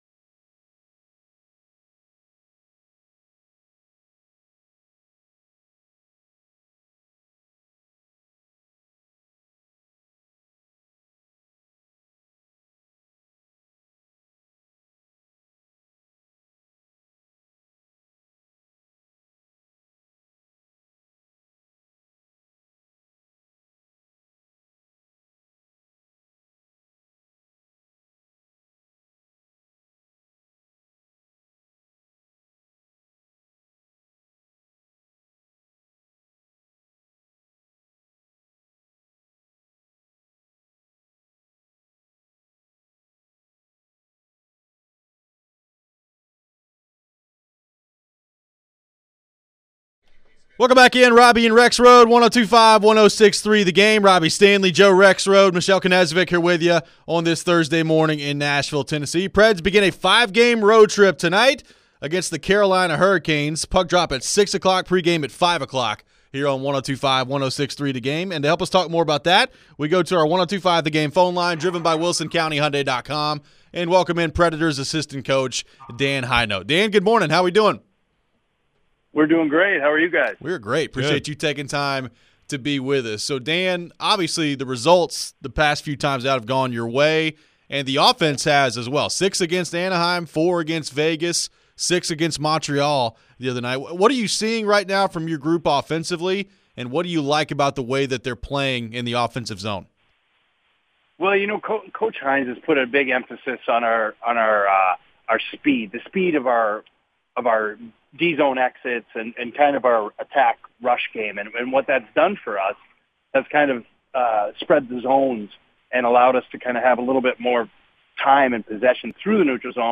Interview with Preds Asst.